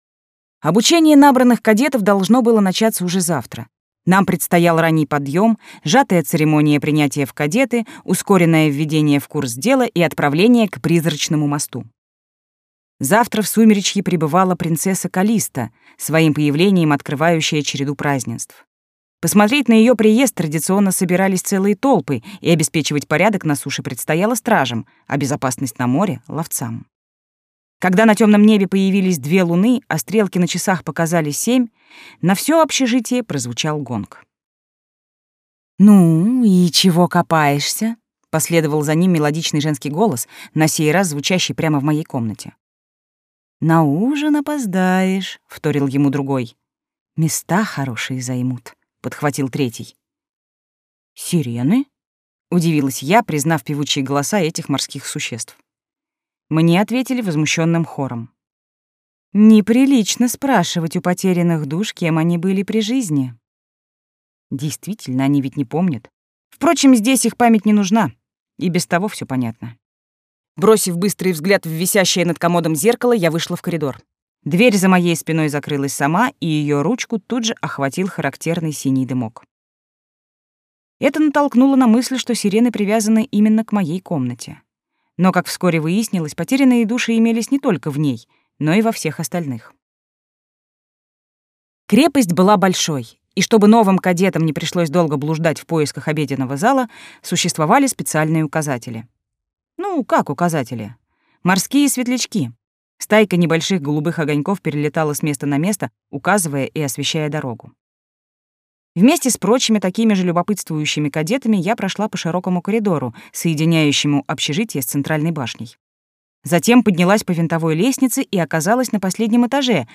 Аудиокнига Сумеречье. Девчонка из Слезных трущоб | Библиотека аудиокниг